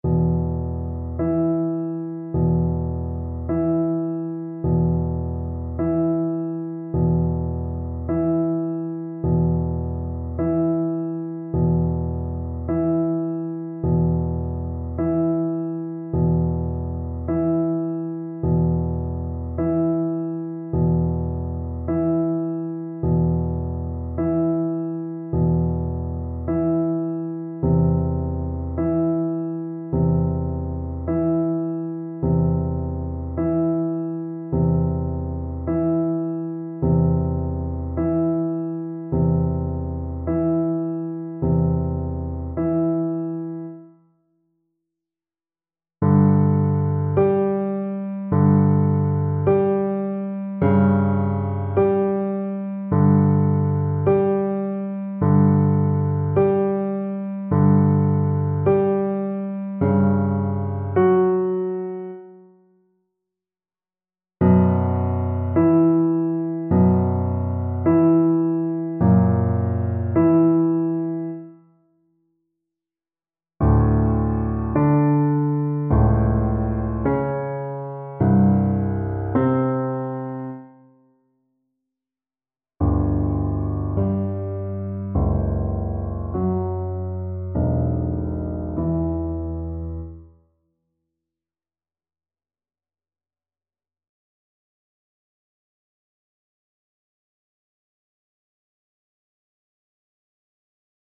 2/4 (View more 2/4 Music)
Andante =90
Classical (View more Classical Viola Music)